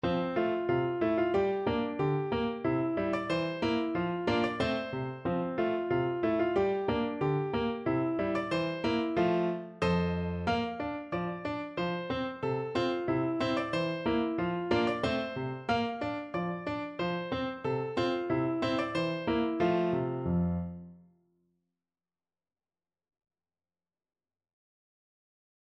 Piano version
Refrain Time Signature: 2/2 ( View more 2/2 Music ) Score Key: F major (Sounding Pitch) ( View more F major Music for Piano ) Tempo Marking: Jolly =c.92 Duration: 0:21 Number of Pages: 1 Difficulty: Easy Level: Recommended for Be
vo_luzern_uf_waggis_zue_PNO.mp3